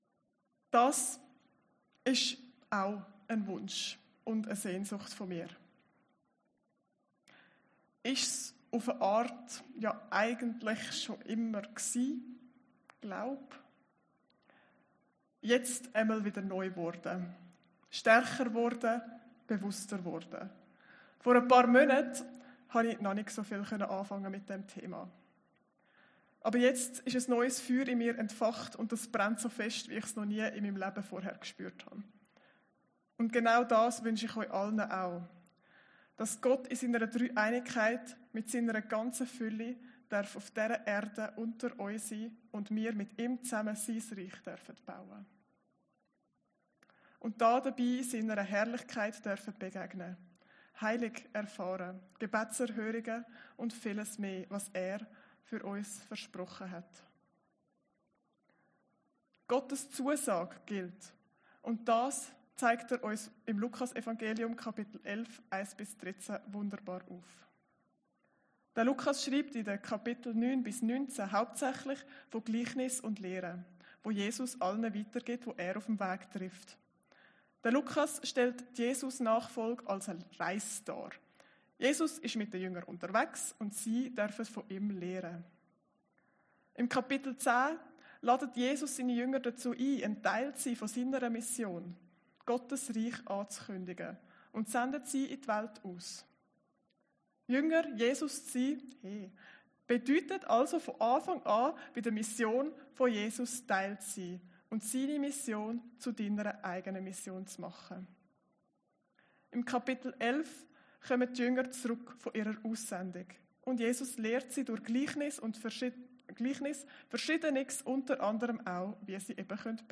Predigten Heilsarmee Aargau Süd – Beten, wie Jesus es lehrte